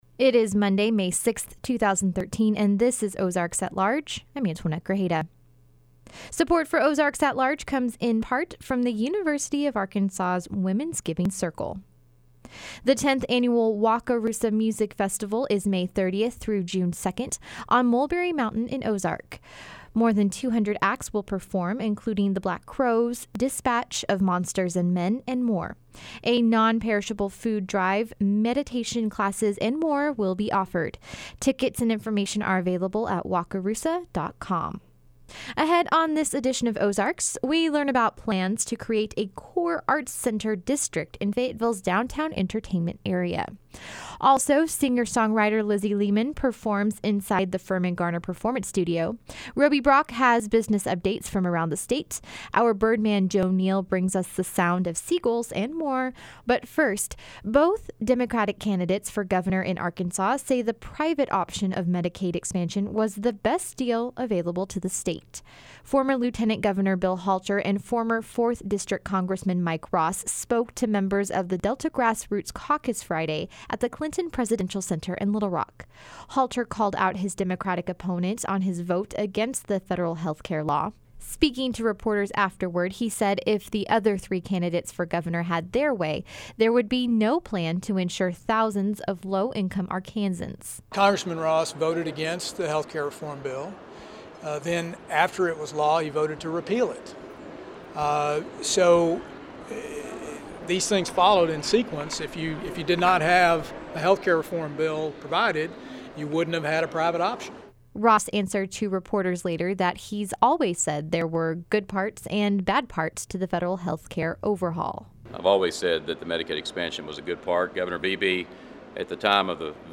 performs inside the Firmin-Garner Performance Studio.